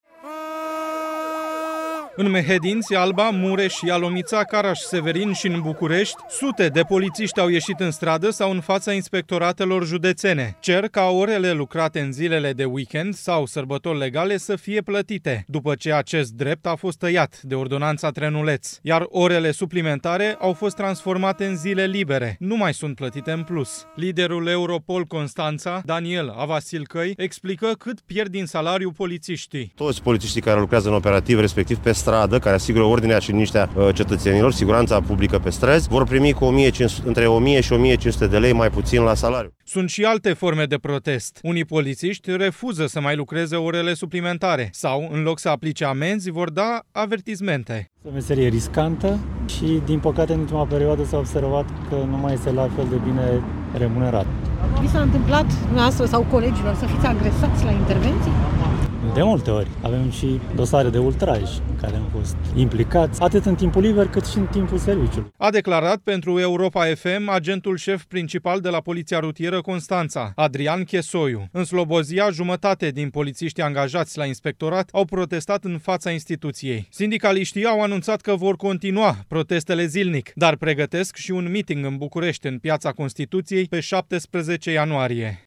În Mehedinți, Alba, Mureș, Ialomița, Caraș-Severin și în București sute de polițiști au ieșit în stradă sau în fața Inspectoratelor Județene.